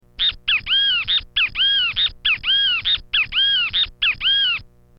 Bem-Te-Vi Cantando
Som do pássaro Bem-Te-Vi.
bem-te-vi-cantando.mp3